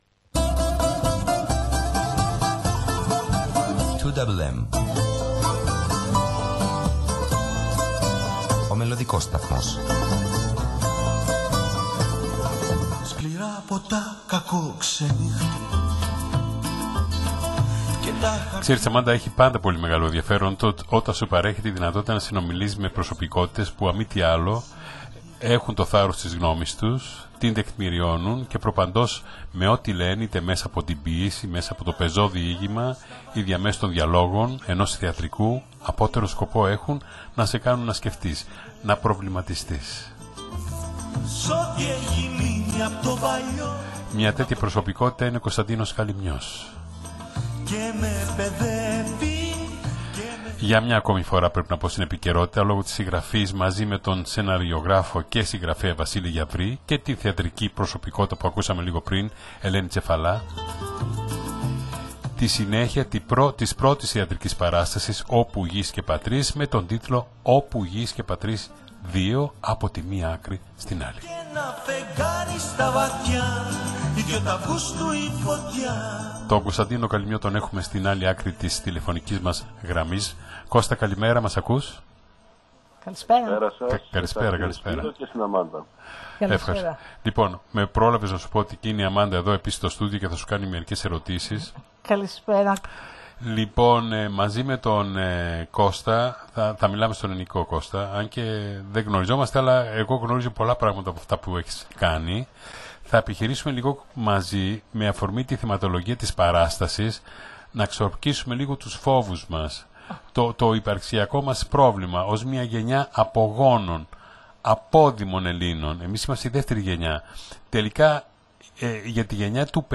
Μία ζωντανή συζήτηση στην διάρκεια της ραδιοφωνικής εκπομπής ” Νυκτερινοί Περίπατοι Ραδιοφώνου”